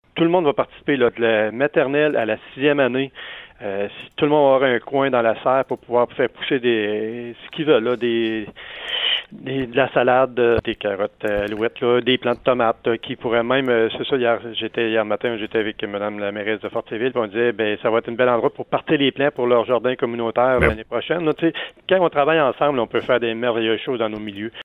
Le maire de Sainte-Françoise, Mario Lyonnais :